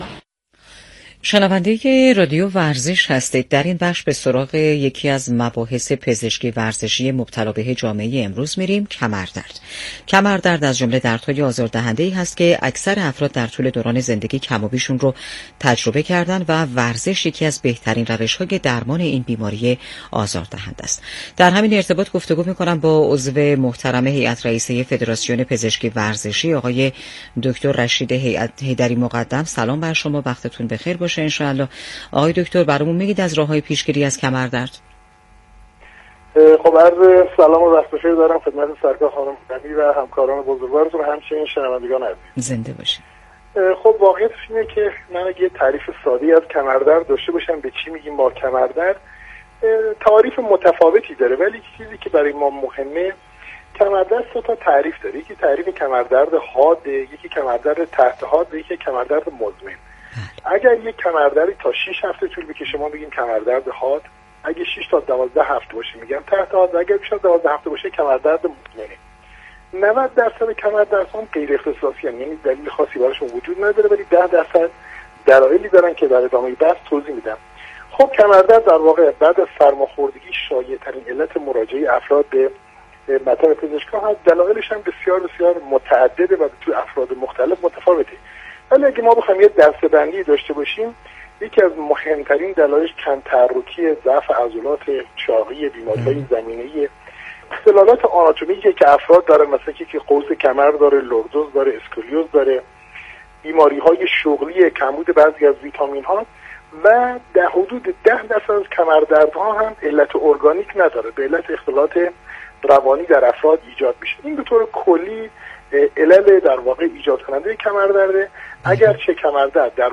در گفت گو با رادیو ورزش تبیین کرد؛